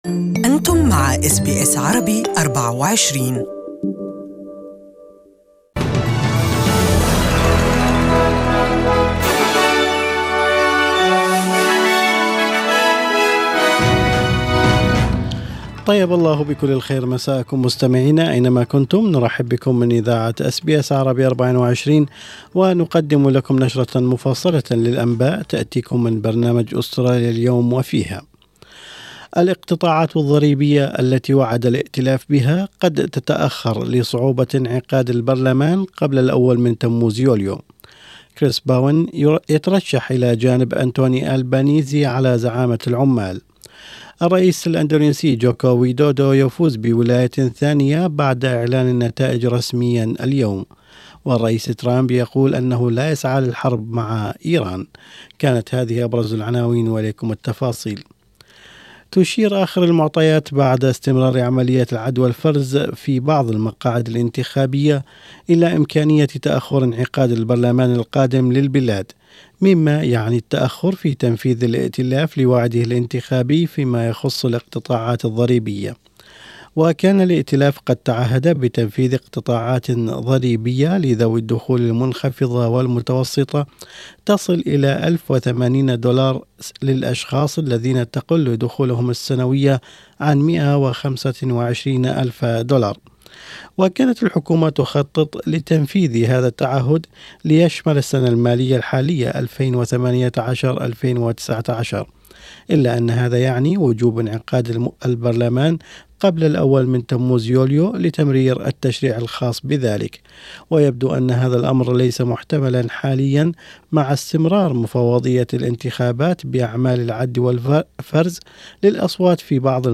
Evening News from SBS Arabic24